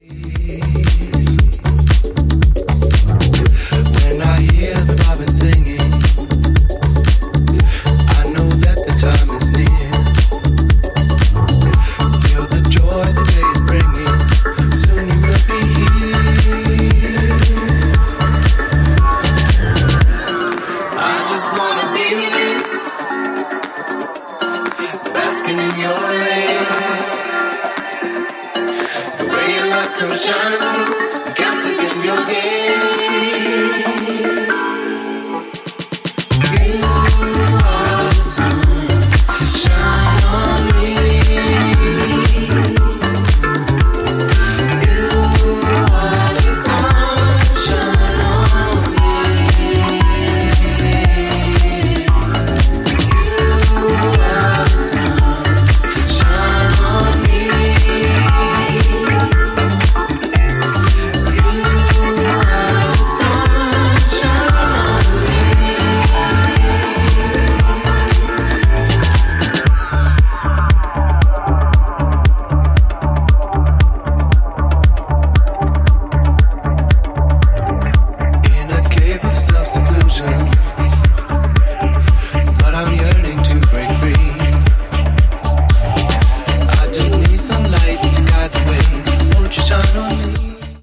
ジャンル(スタイル) NU DISCO / DISCO / BALEARICA / EDITS